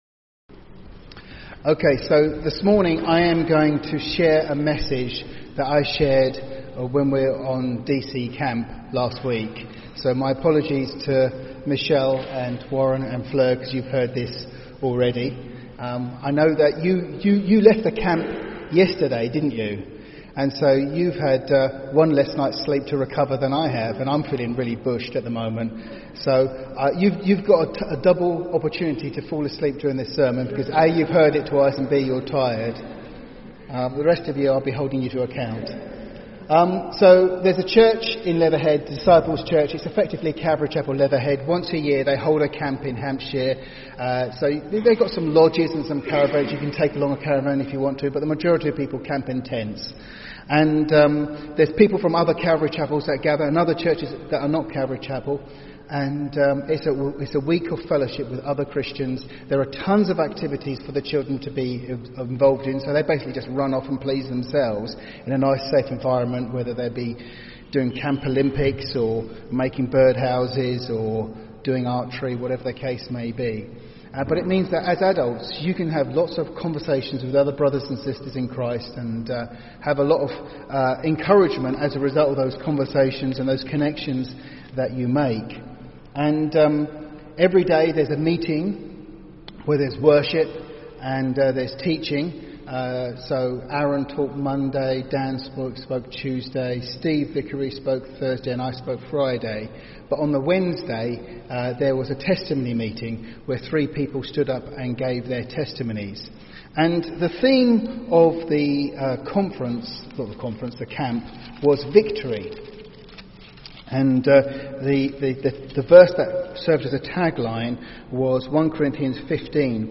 Listen to Audio Sermon Series: Special Topics 1 Corinthians 15:57 declares: “But thanks be to God!